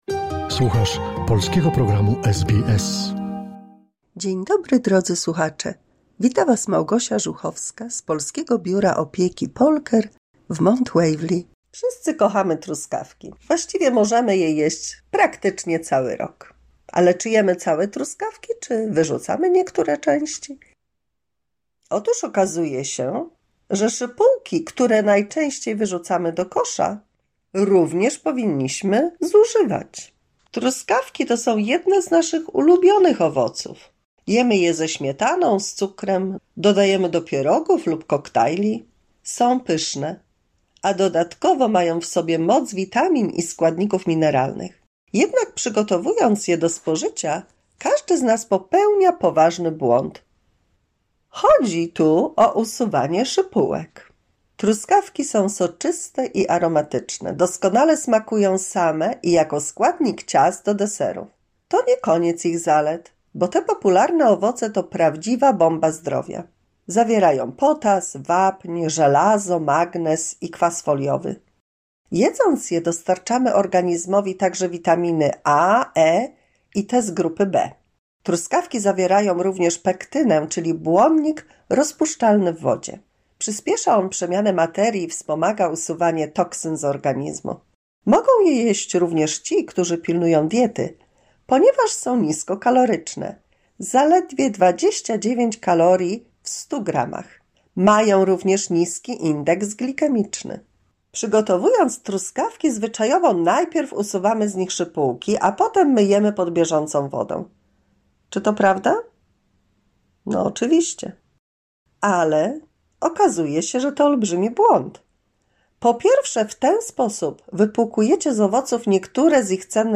159 mini słuchowisko dla polskich seniorów